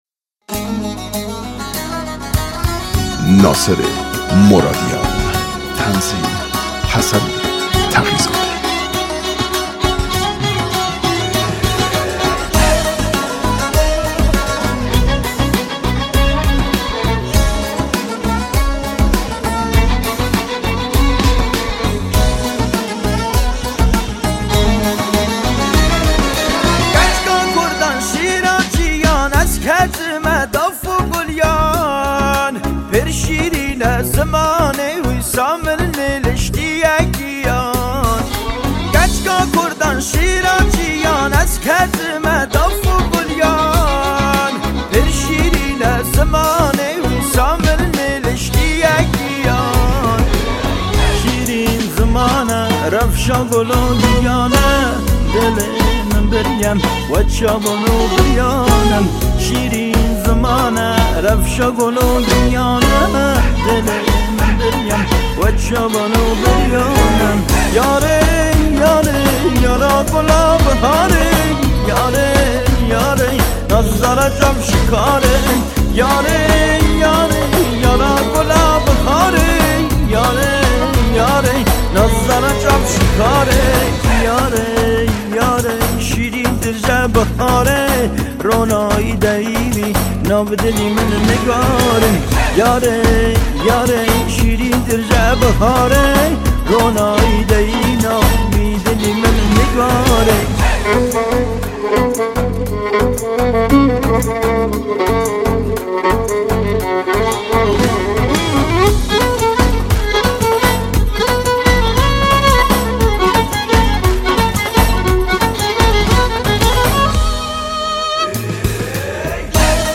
آهنگ کرمانجی
آهنگ مشهدی